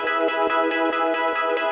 SaS_MovingPad01_140-E.wav